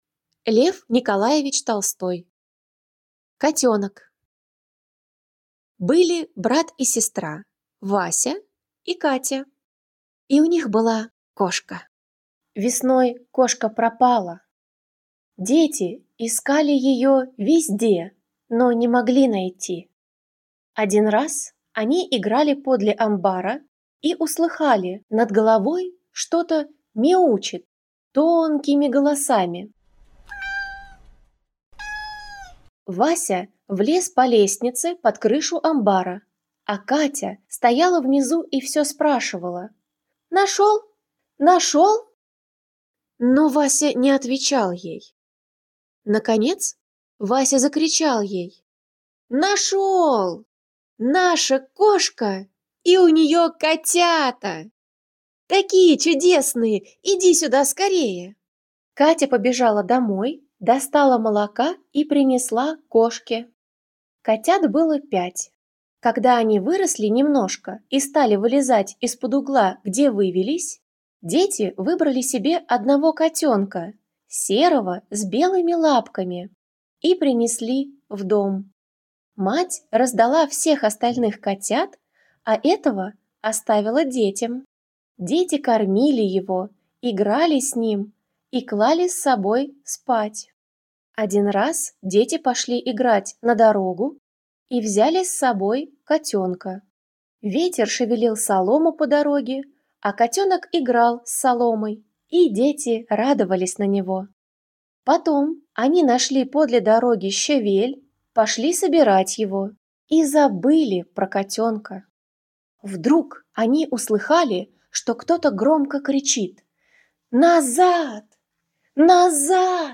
В честь этого дня в рамках проекта «Волонтёры читают» мы подготовили подарок для всех любителей животных и литературы — аудиорассказы Льва Николаевича Толстого «Котёнок» и «Булька»!